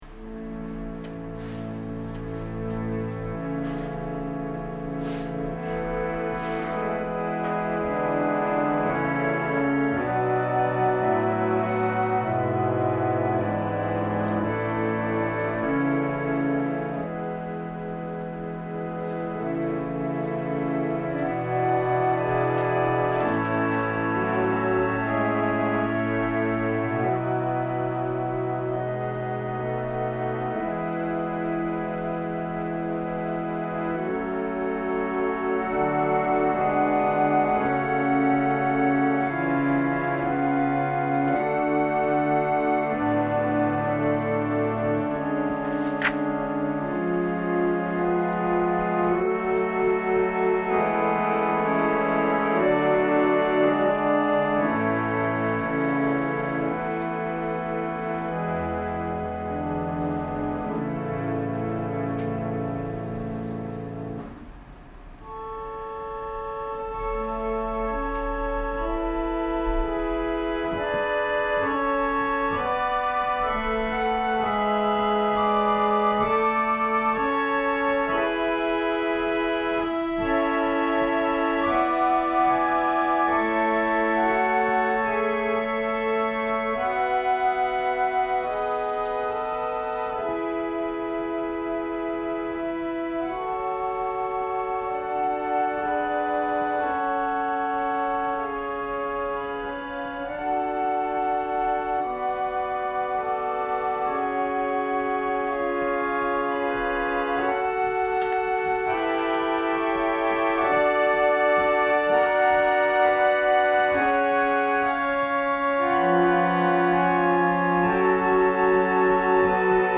説教アーカイブ。
埼玉県春日部市のプロテスタント教会。
音声ファイル 礼拝説教を録音した音声ファイルを公開しています。